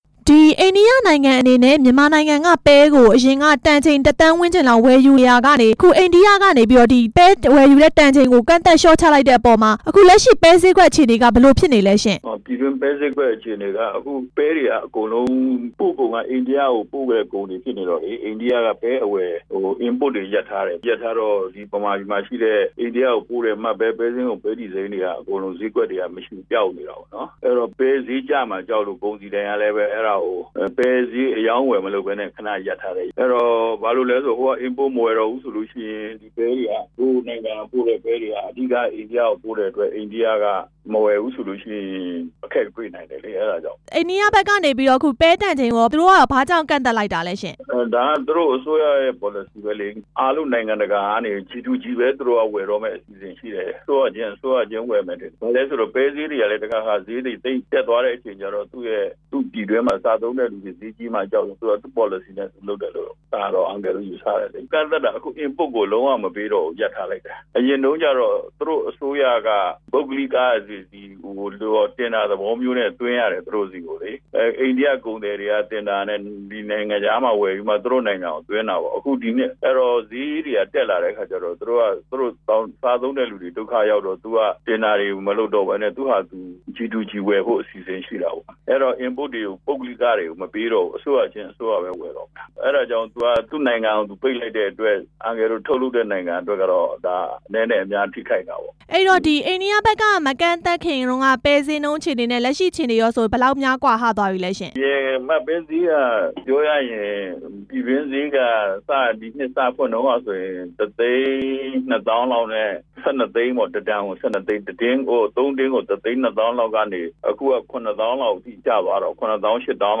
မြန်မာ့ပဲဈေးကွက် ရပ်ဆိုင်းထားရတဲ့အကြောင်း မေးမြန်းချက်